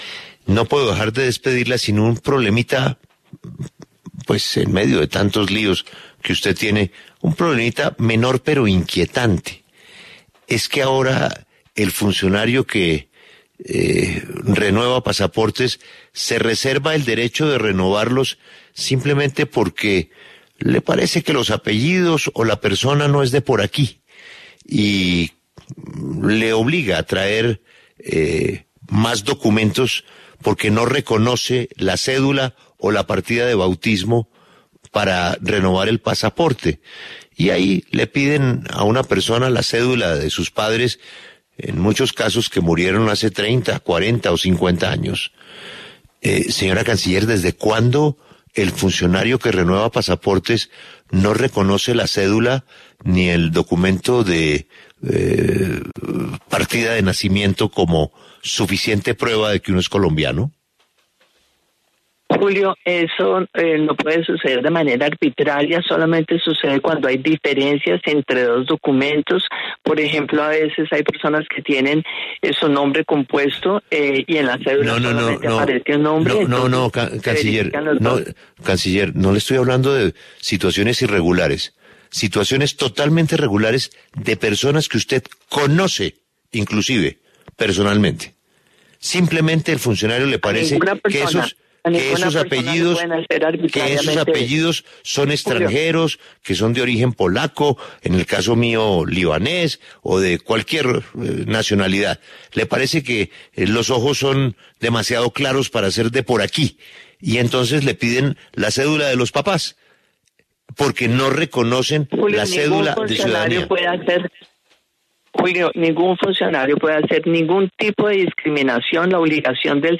La canciller y vicepresidente Marta Lucía Ramírez aseguró en los micrófonos de W Radio que ningún funcionario tiene la autorización para hacer exigencias inusuales.
Lo invitamos a que escuche a continuación la entrevista completa a la vicepresidenta y canciller Marta Lucía Ramírez en La W: